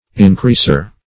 Increaser \In*creas"er\, n.